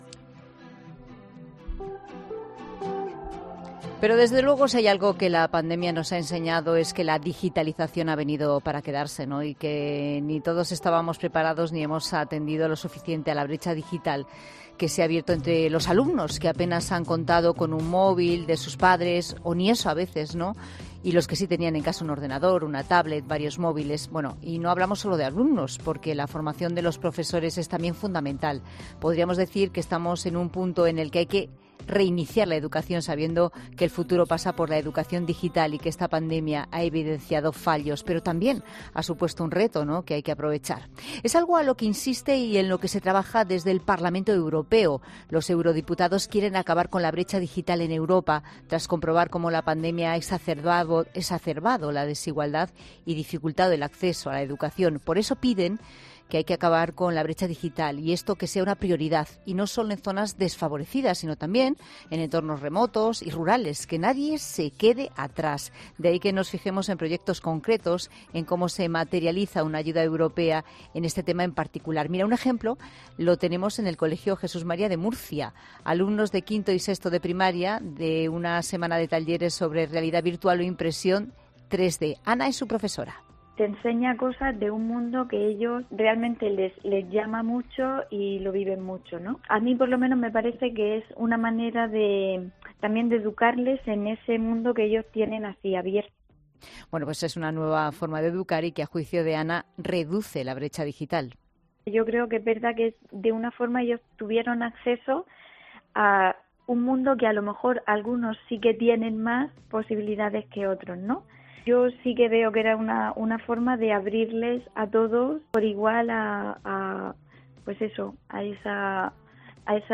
En 'La Tarde' hemos hablado con Pilar del Castillo, eurodiputada, que nos ha contado que "la primera ola de la pandemia nos dejó una conclusión fundamental. Era necesario y urgente que todos los alumnos cuenten con unos conocimientos digitales básicos para conducirse en un aprendizaje de carácter online y tienen los dispositivos electrónicos que les permiten acceder a Internet y la cobertura de red".